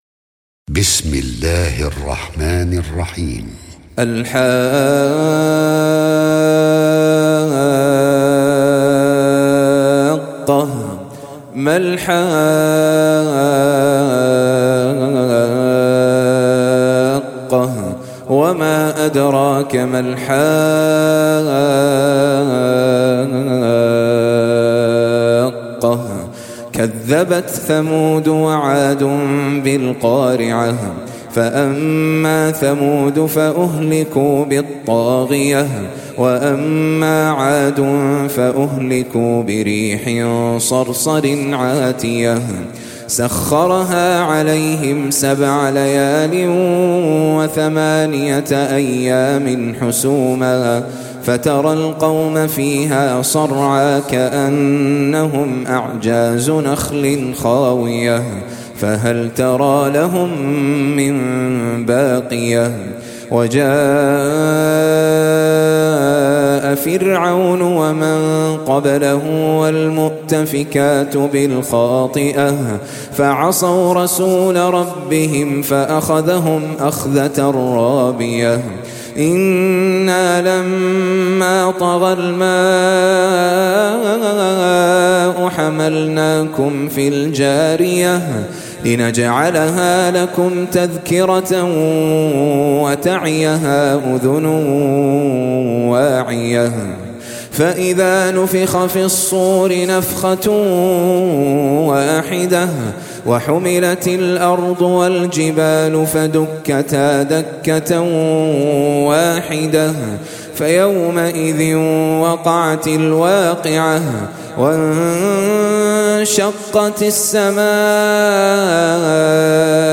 Surah Al-H�qqah سورة الحاقة Audio Quran Tarteel Recitation
Surah Sequence تتابع السورة Download Surah حمّل السورة Reciting Murattalah Audio for 69.